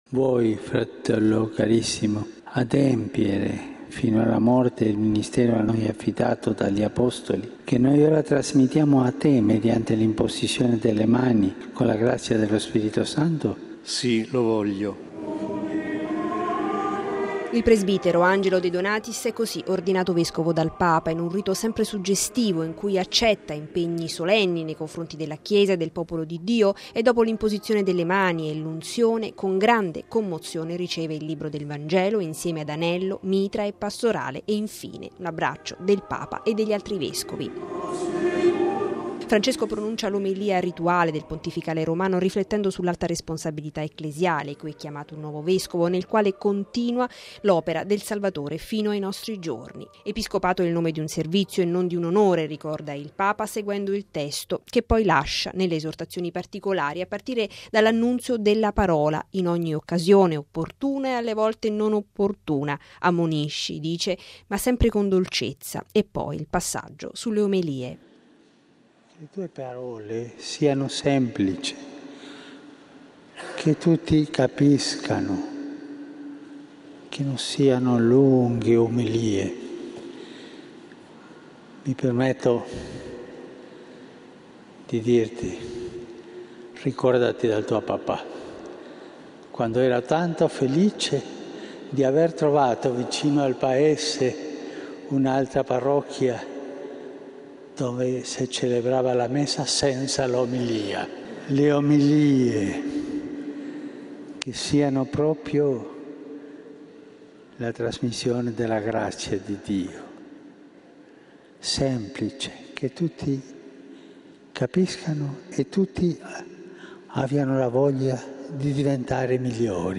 Il Papa ha presieduto ieri pomeriggio in San Giovanni in Laterano, nel giorno della festa della Dedicazione della Basilica cattedrale di Roma, la Messa per l’ordinazione episcopale di mons. Angelo De Donatis.